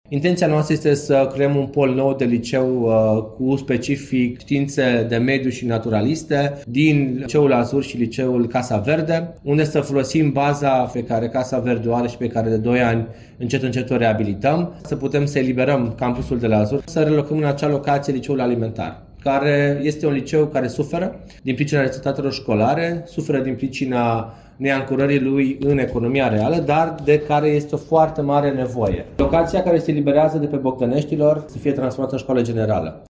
Viceprimarul municipiulu Timișoara a explicat principalele puncte ale reorganizării rețelei școlare.